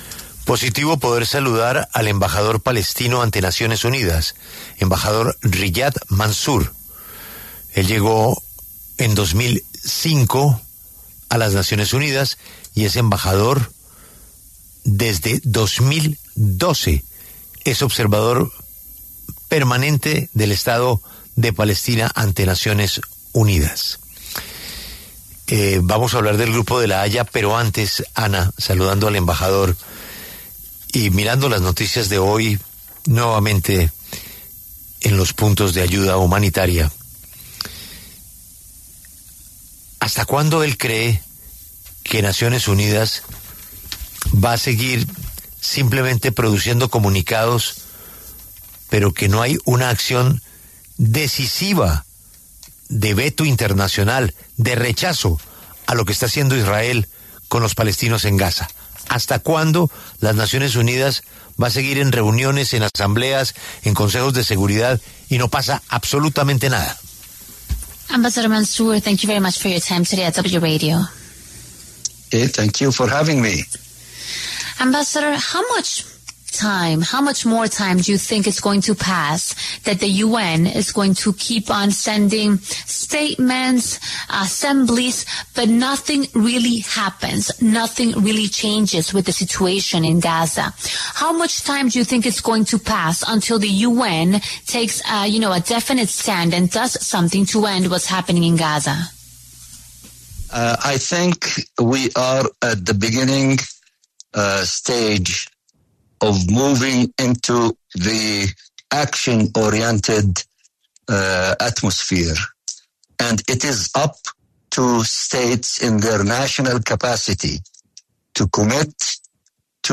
Riyad Mansour, el representante diplomático de Palestina ante las Naciones Unidas, se refirió a la situación en la Franja de Gaza y el rol de otros países en conversación con La W.